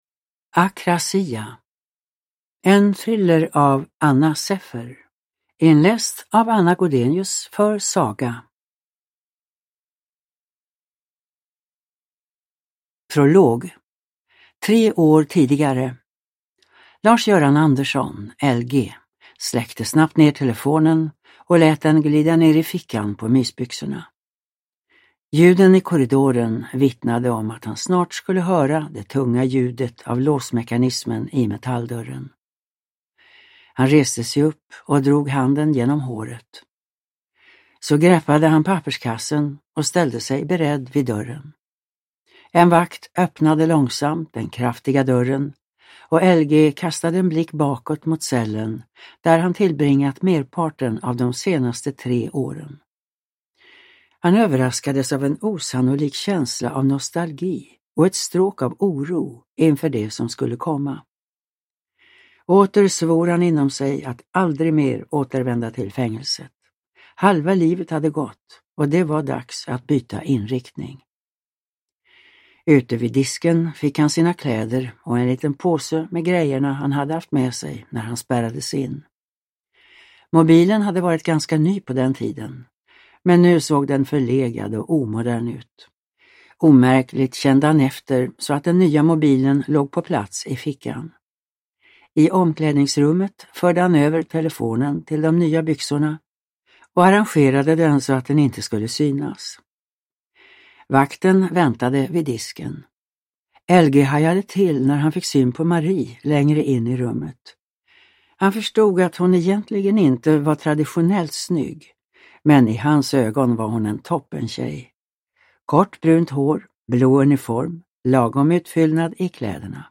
Akrasia / Ljudbok